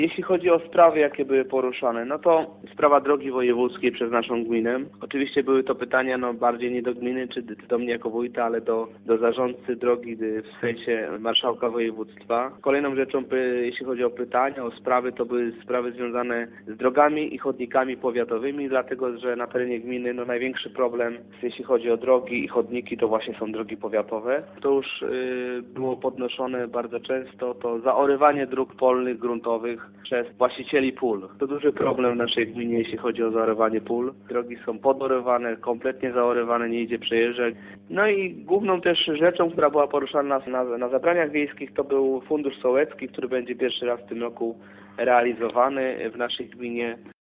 Podobnie było na terenie gminy Dąbrowa, mówi wójt Marcin Barczykowski.